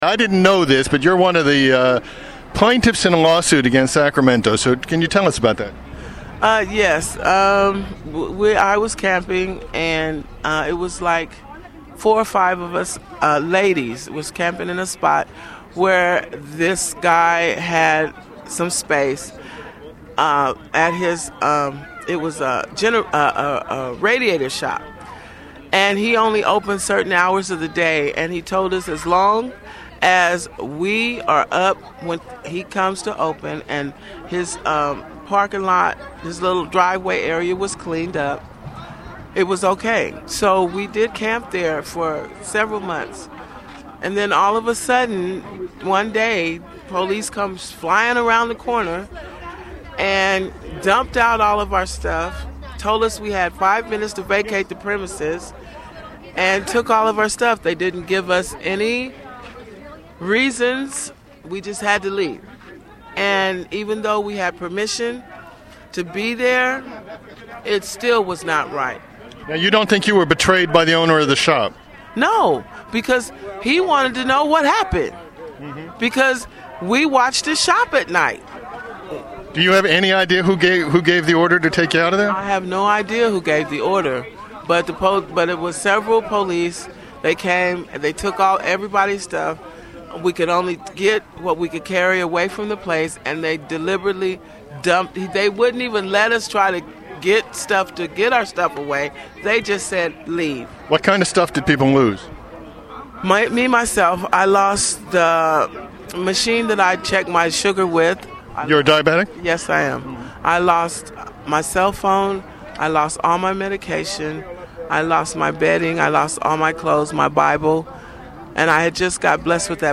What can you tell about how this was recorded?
This interview was done at Loaves and Fishes in Sacramento, just before the “Safe Ground” rally on April 20.